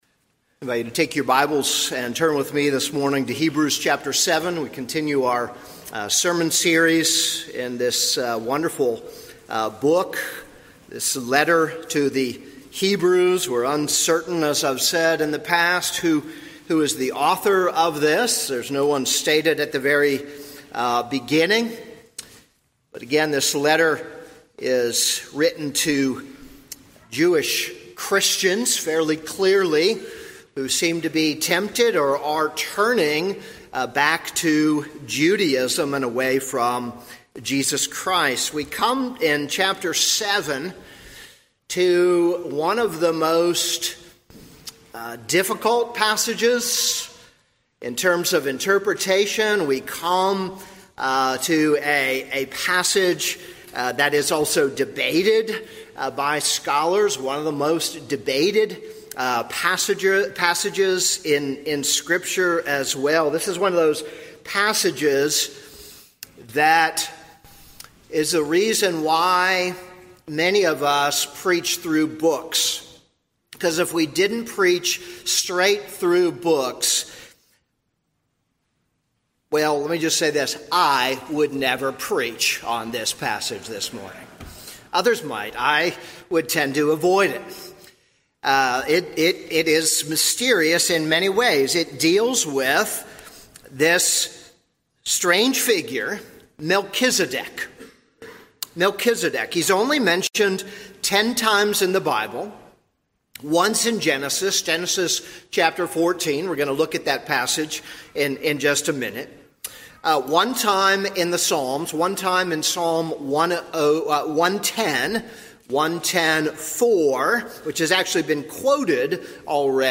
This is a sermon on Hebrews 7:1-10.